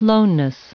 Prononciation du mot loneness en anglais (fichier audio)
Prononciation du mot : loneness